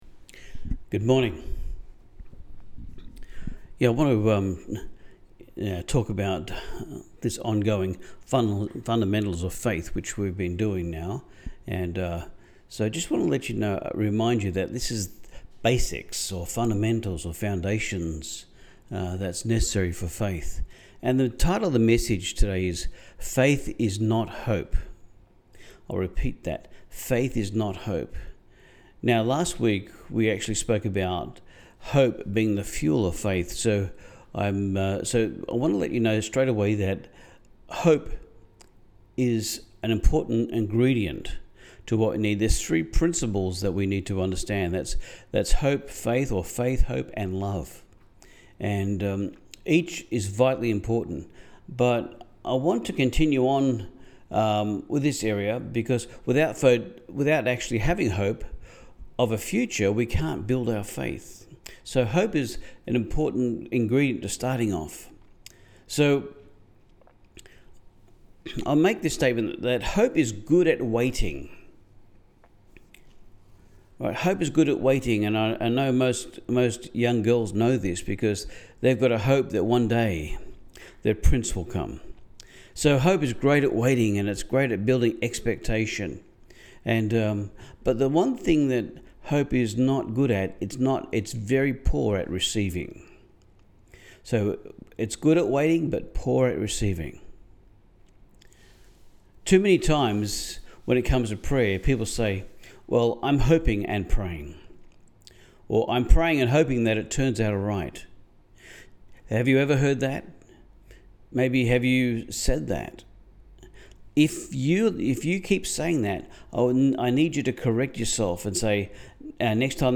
Hebrews 11:1 Service Type: Sunday Service Friends